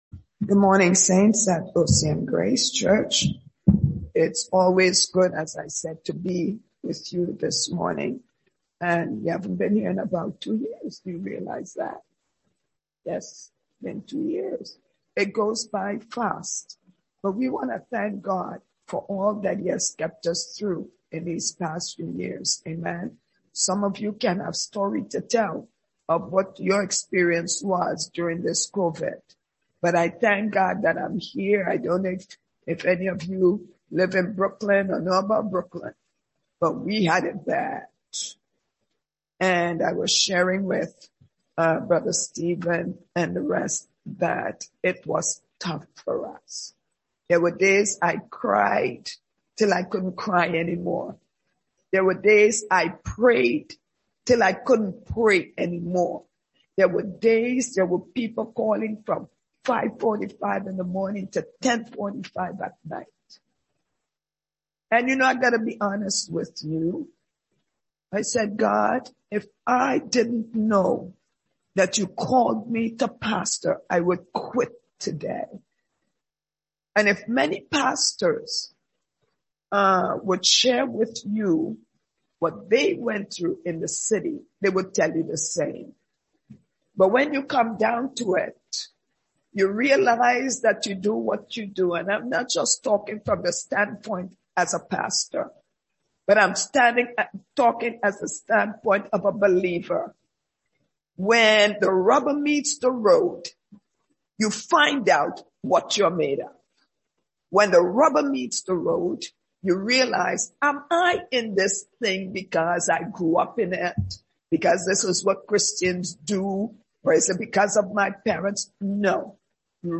Video: Sunday English Worship Video